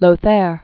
(lō-thâr, -târ) 795?-855.